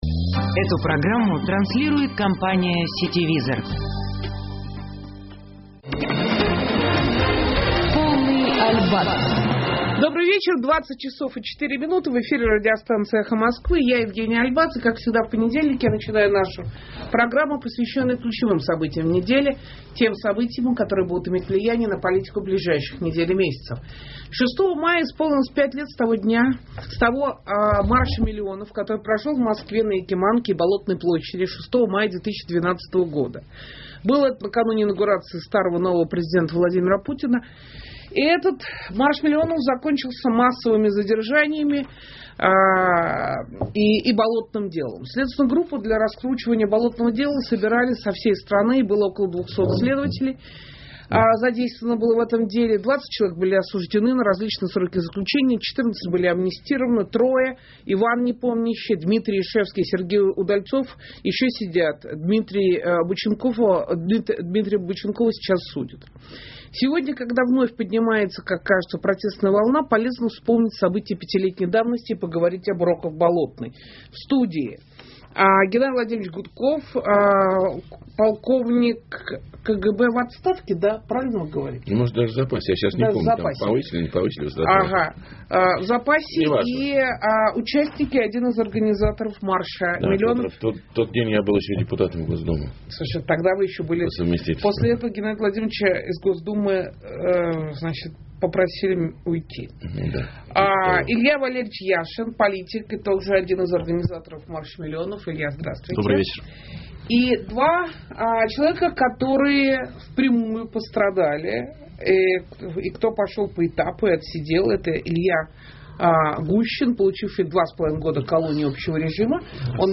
В прямом эфире радиостанции «Эхо Москвы»